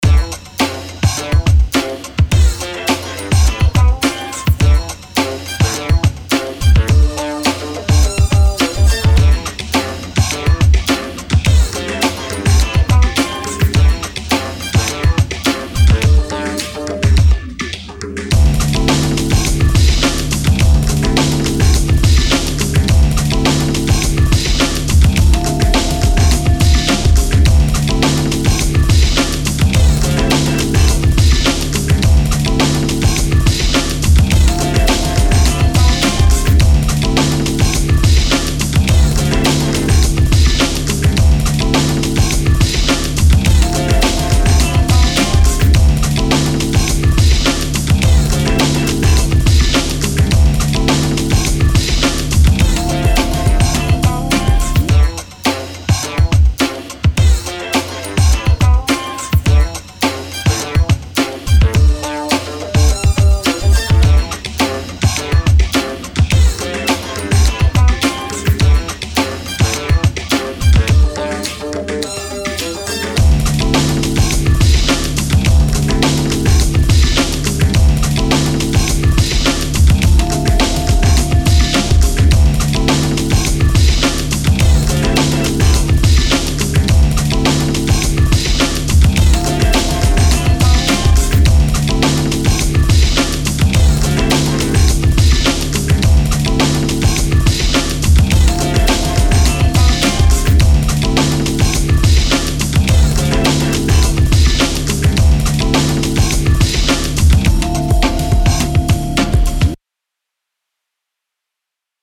Music / Rap
hip-hop